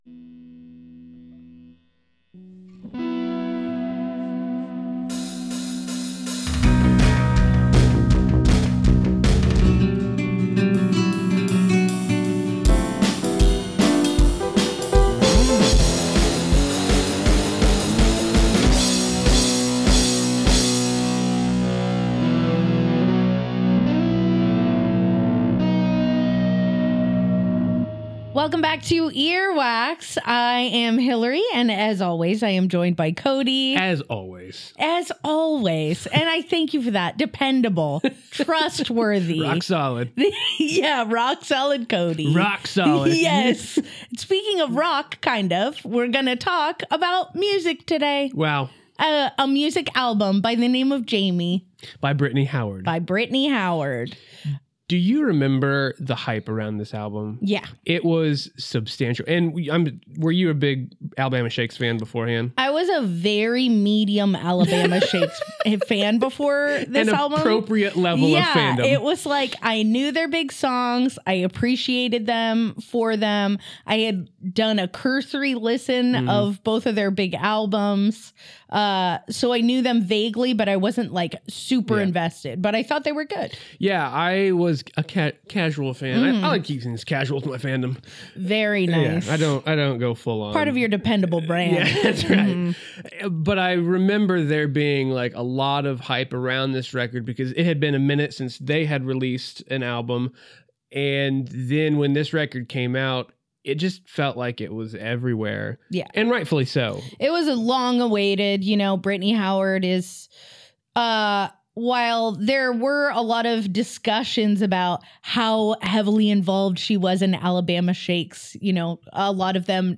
So how about an awesome interview with one of the coolest bands out there today?